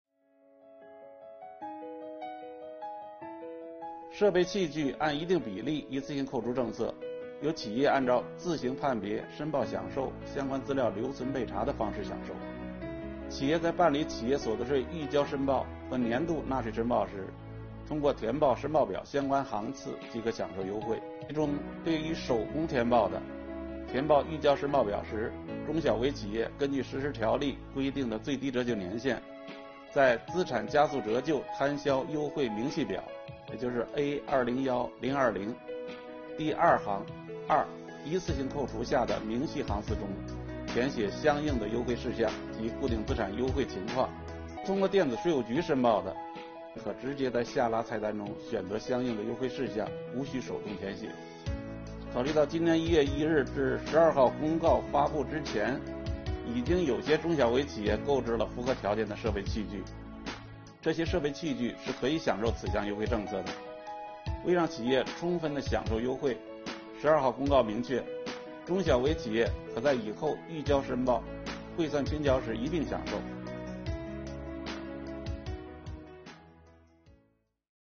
本期课程由国家税务总局所得税司一级巡视员刘宝柱担任主讲人，解读中小微企业购置设备器具按一定比例一次性税前扣除政策。今天，我们一起学习：中小微企业申报享受设备器具税前扣除优惠的时点和方式。